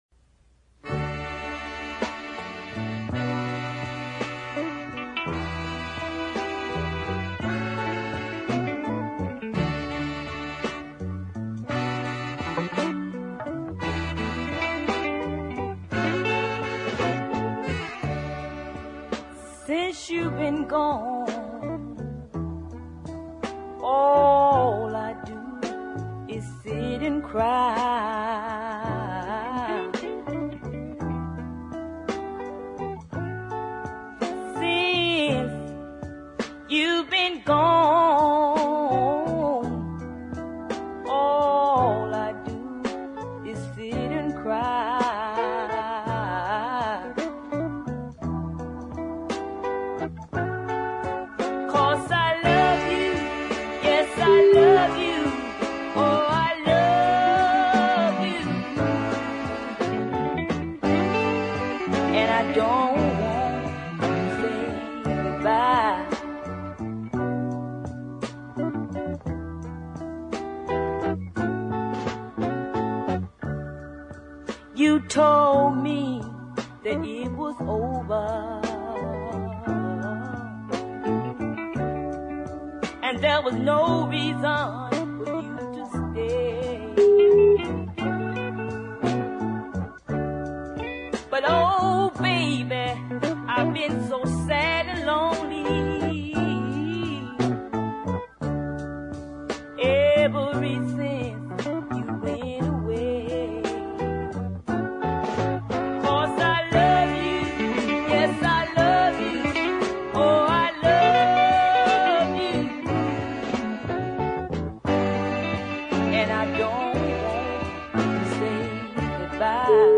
gentle ballad
the downward chord changes give the song a nice "blue" feel